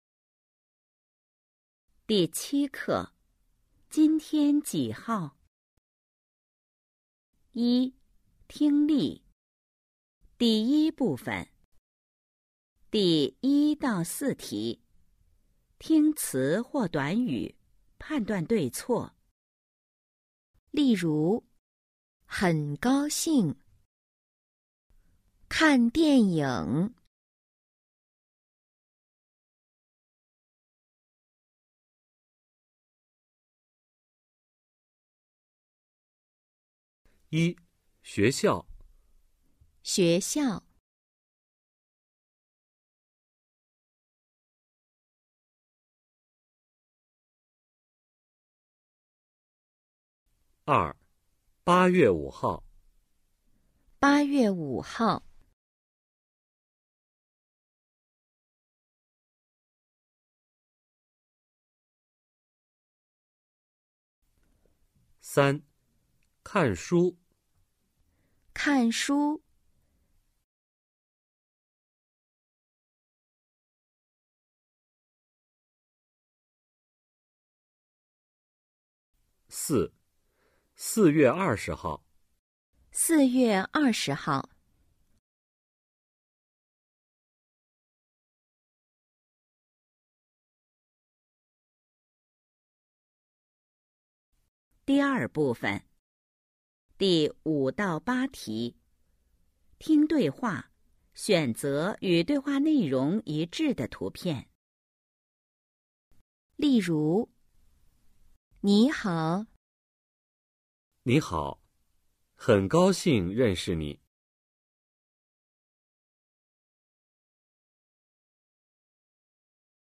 一、听力 Phần nghe 🎧 07-1